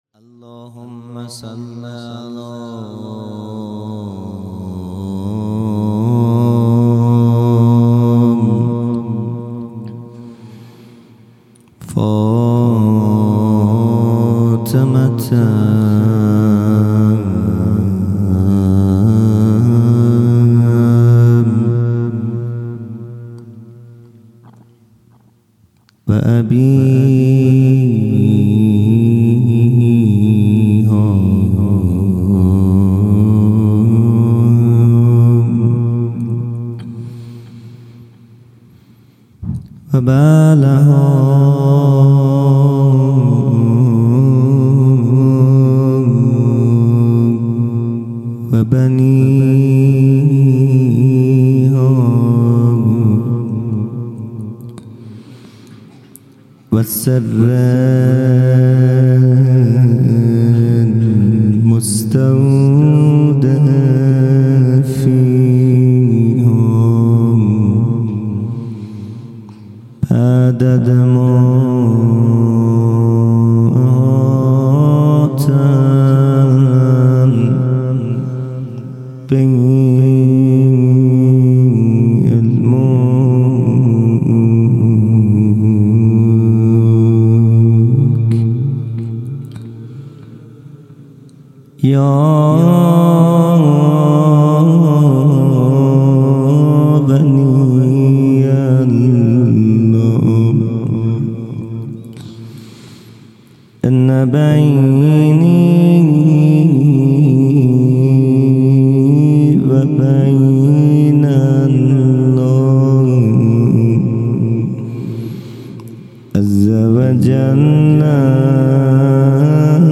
مناجات
جلسۀ هفتگی | به یاد شهید همت